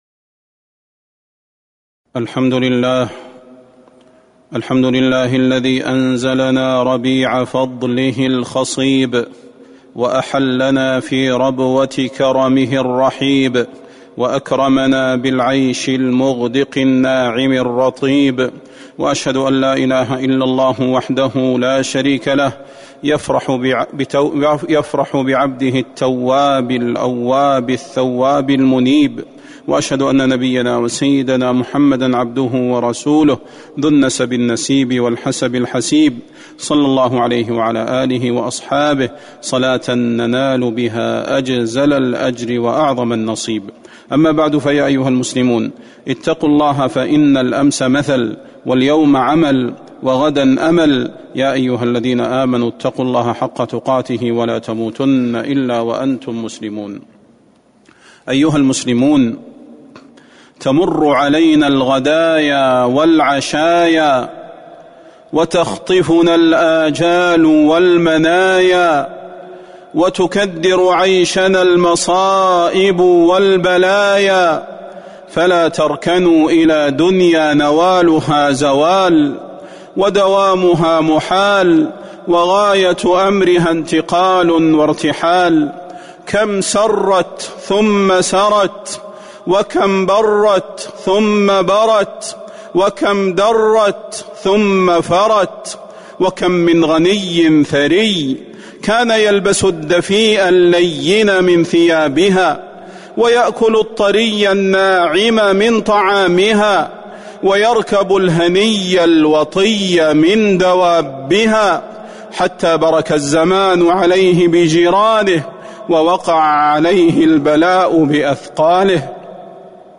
تاريخ النشر ٢٩ ذو القعدة ١٤٤٢ هـ المكان: المسجد النبوي الشيخ: فضيلة الشيخ د. صلاح بن محمد البدير فضيلة الشيخ د. صلاح بن محمد البدير التحذير من دار الغرور